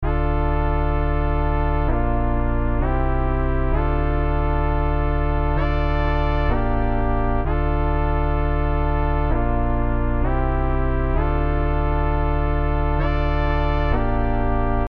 D小调和弦合成器
描述：dminor的hord合成器循环； 我把它归入舞蹈类，但它可以用于许多不同的风格。
Tag: 130 bpm Dance Loops Synth Loops 2.50 MB wav Key : Unknown